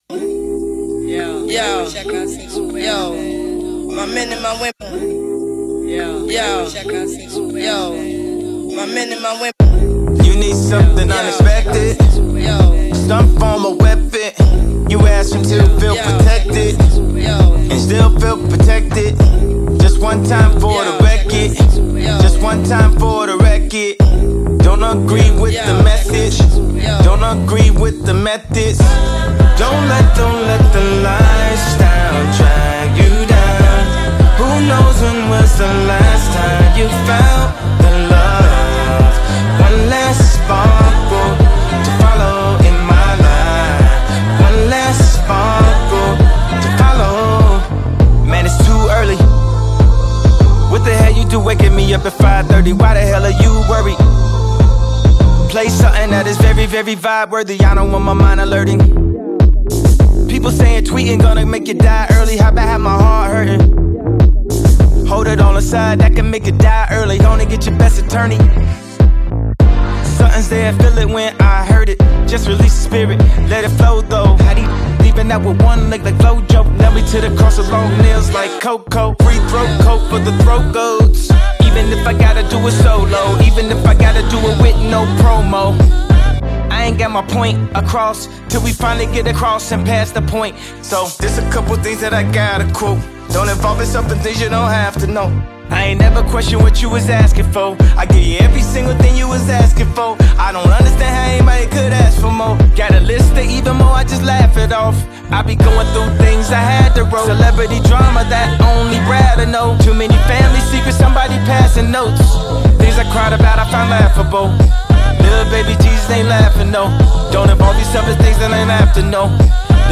progressive rap
Hip-Hop